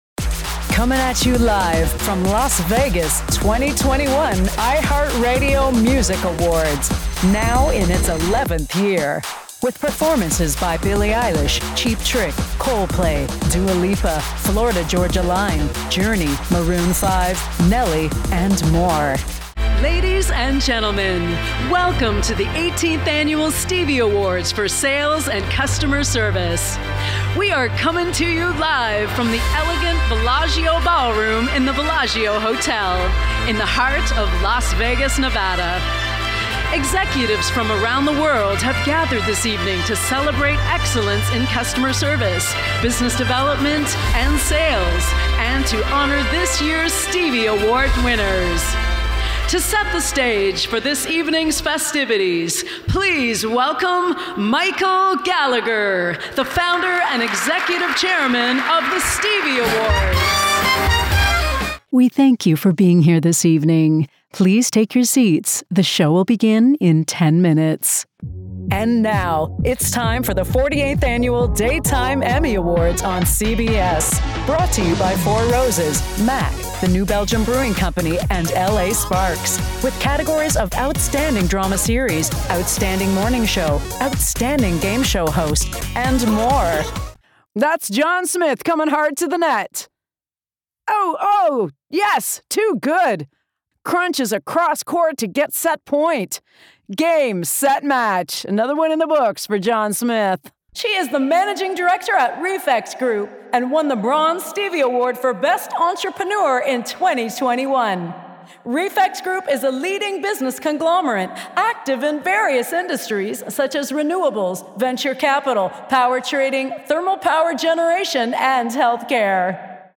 Female Voice Over, Dan Wachs Talent Agency.
Warm, sassy, gritty, real, mature.
Voice of God